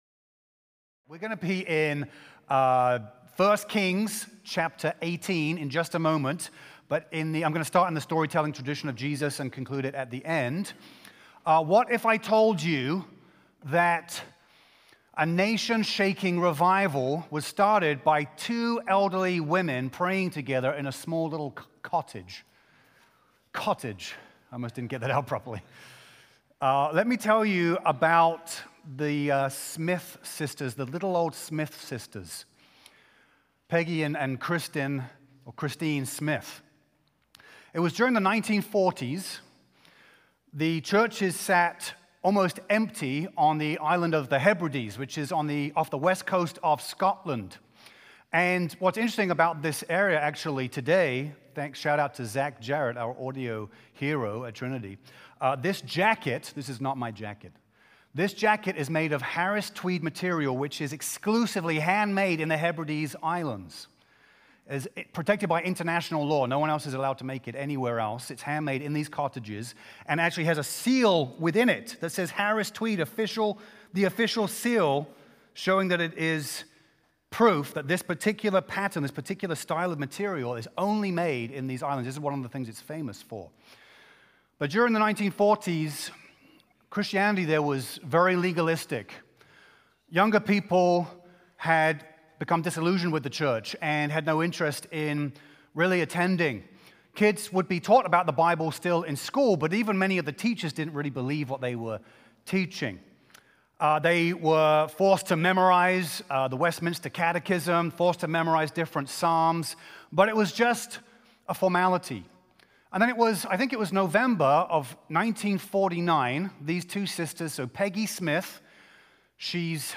A message from the series "Revival Times."